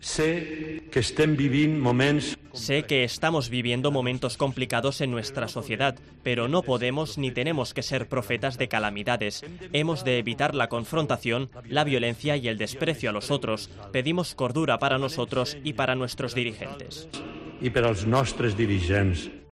El Cardenal Arzobispo de Barcelona, Juan José Omella, pide cordura para afrontar la situación que vive Cataluña. En plena crisis por el referéndum ilegal, monseñor Omella en su Homilía con motivo de la festividad de Nuestra Señora de la Merced, ha hecho un llamamiento para evitar la confrontación.
El Cardenal ha pedido ayuda divina para afrontar las dificultades ante las 800 personas que han llenado la Basílica de la Merced.